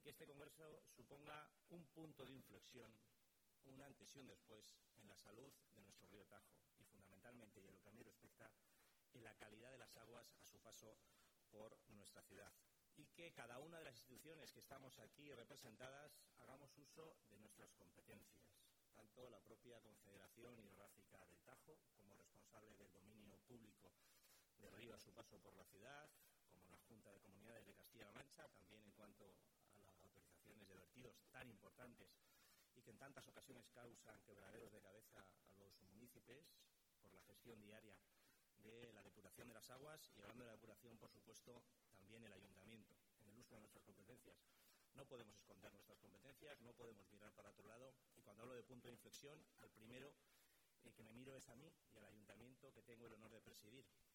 Así lo ha manifestado en el IV Congreso Ibérico de Restauración Fluvial RESTAURARÍOS 2023, que se celebra en el Palacio de Congresos de Toledo los días 21, 22 y 23 de junio, en el que ha participado junto a la vicealcaldesa, Inés Cañizares, y el concejal del Río Tajo, Medio Ambiente y Deportes, Rubén Lozano.
Cortes de Voz
el-alcalde-de-toledo-participa-en-el-iv-congreso-iberico-de-restauracion-fluvial-2.mp3